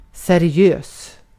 Ääntäminen
IPA : [ɹi.ˈspɛk.tə.bl̩]